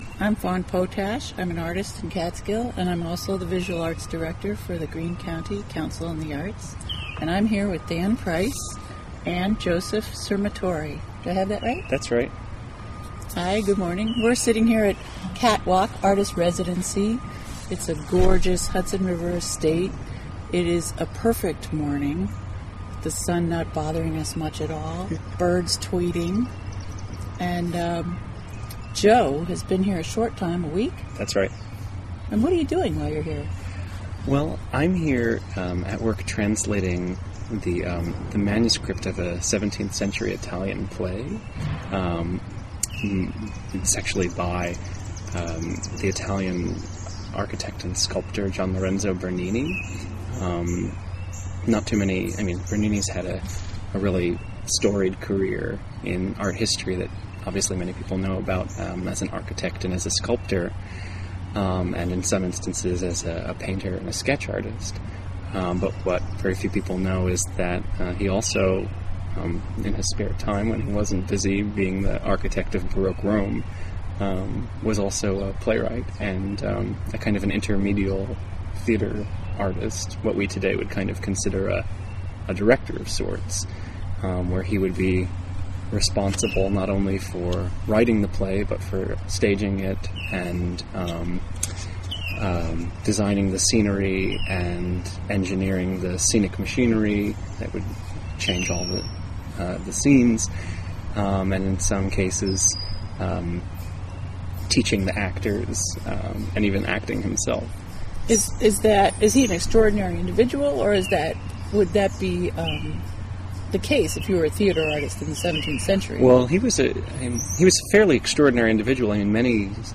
Interviewed
at Catwalk in Catskill.